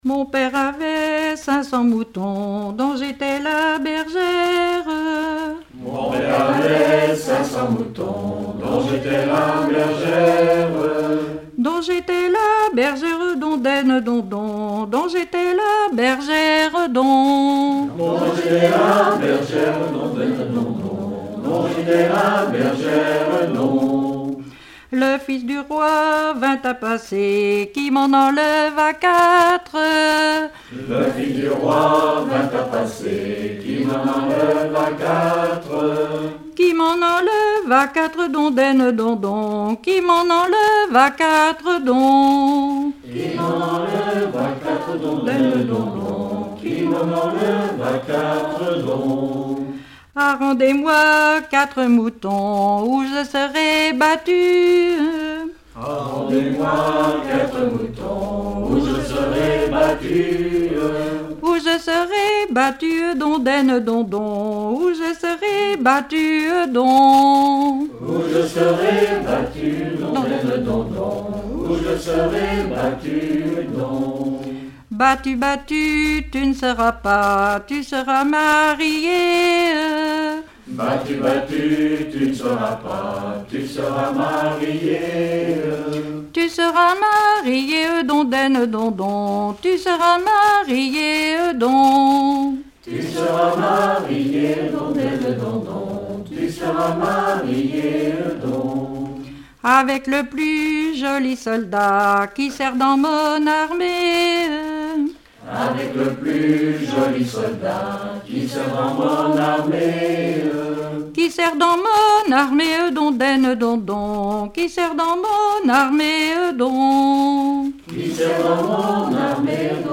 Genre laisse
Collectif de chanteurs du canton - veillée (2ème prise de son)
Pièce musicale inédite